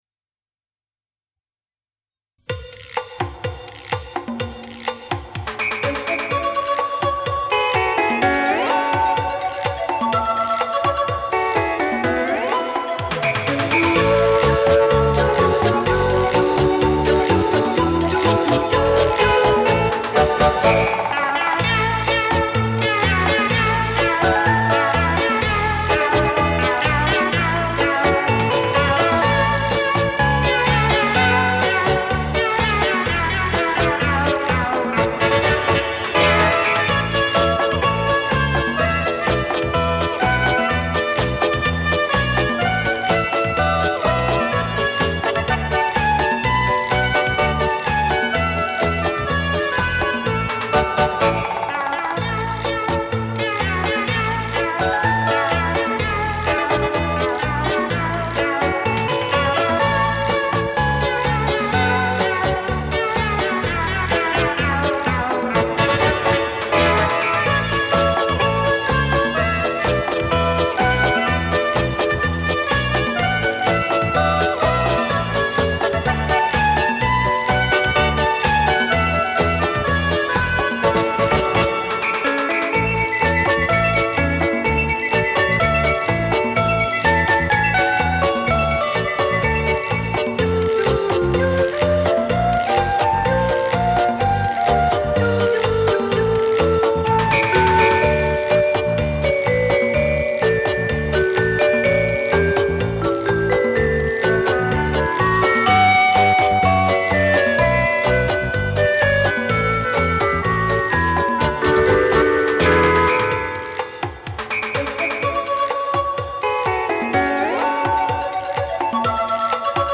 中央各团首席演奏员演奏